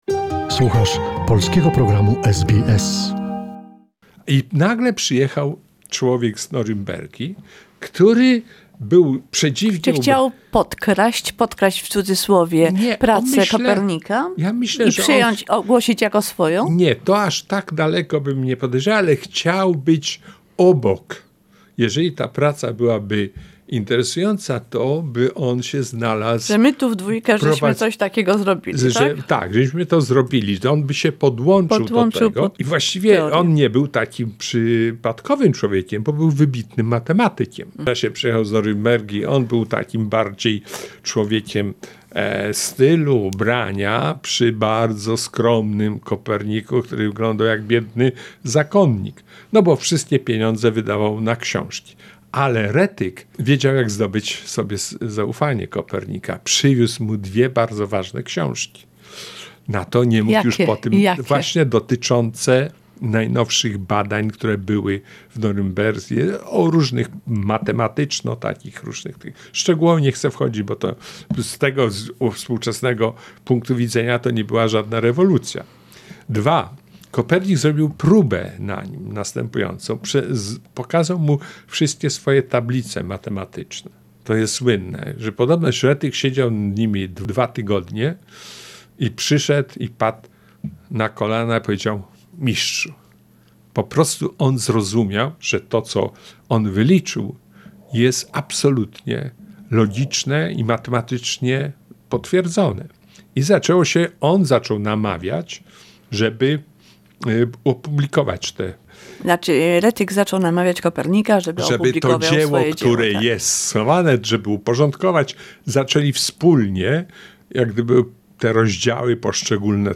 Part three of the conversation with director Jerzy Domaradzki, who is preparing a film about Nicolaus Copernicus: a disturbing visit of a stranger.